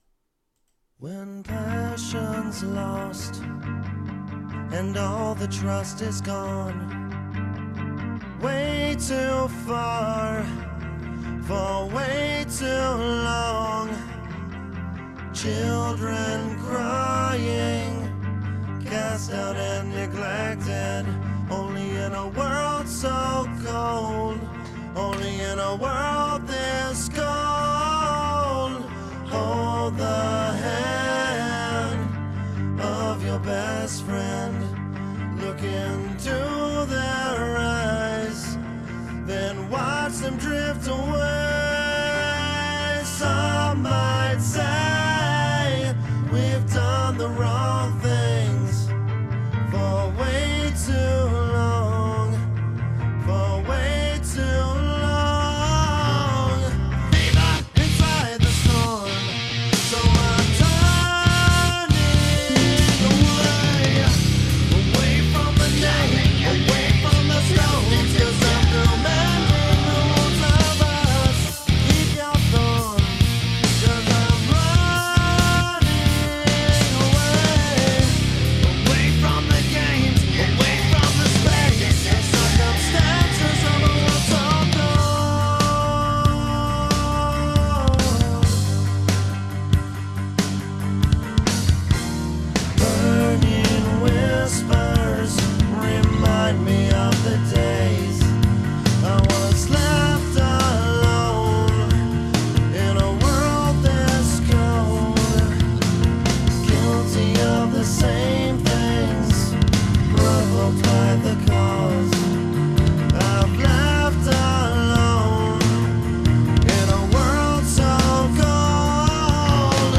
L’Extrême Podcast du 4 septembre 2025 avec RAVENS CREW en interview | All Rock